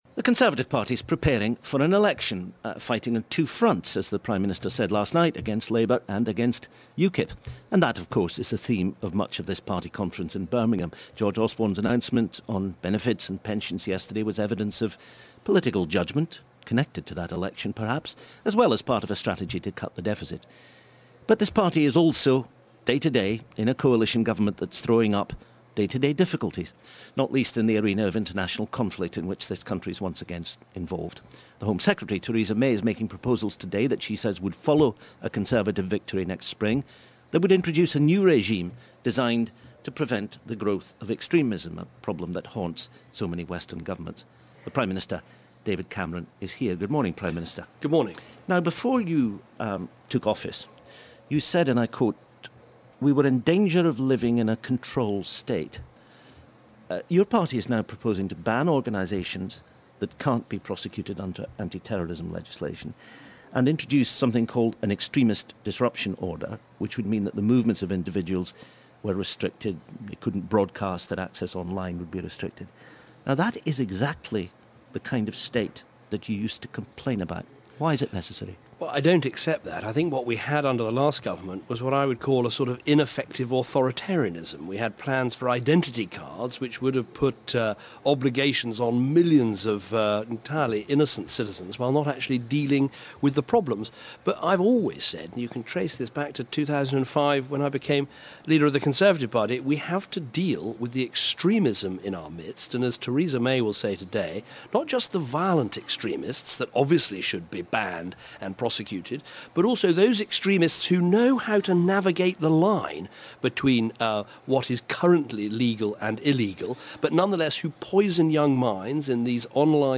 The Prime Minister talks to Radio 4's Today programme at the Conservative party conference in Birmingham.